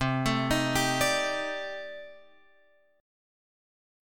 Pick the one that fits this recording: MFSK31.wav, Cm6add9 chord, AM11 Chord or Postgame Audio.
Cm6add9 chord